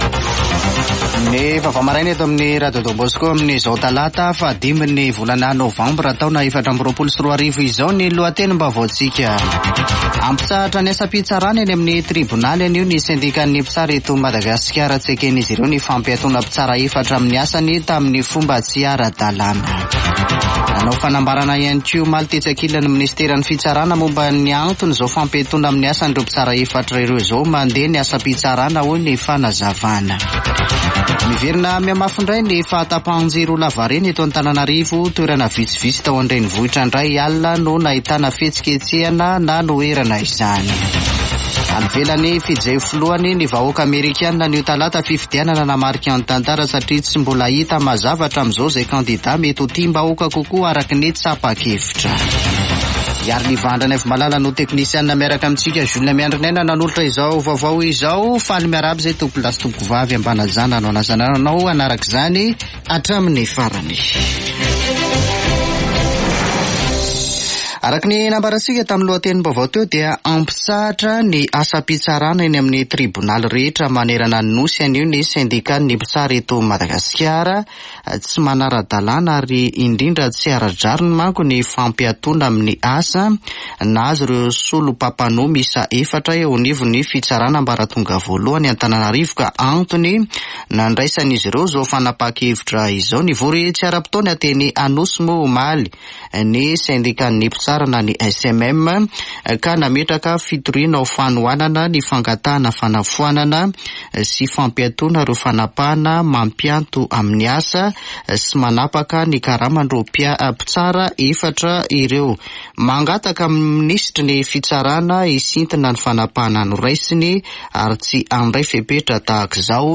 [Vaovao maraina] Talata 5 novambra 2024